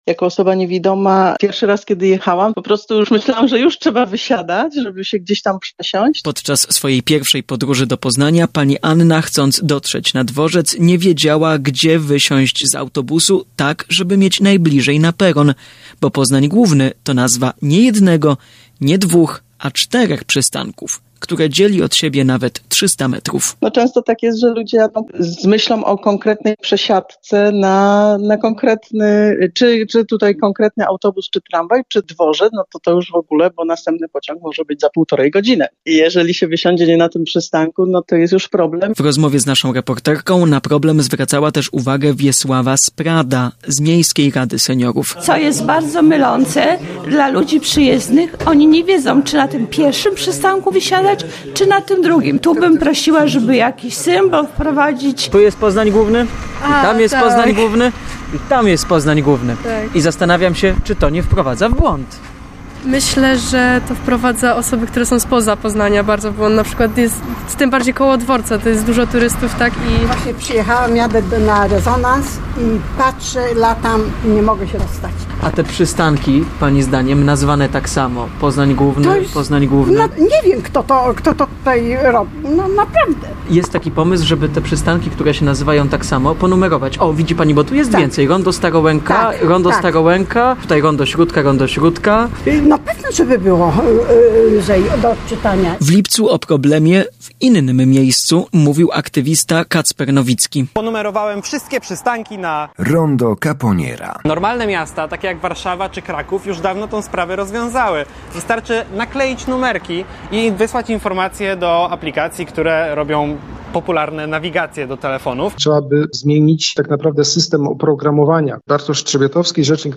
Osoby z którymi rozmawiał nasz reporter przyznają, że niektórym trudno się odnaleźć.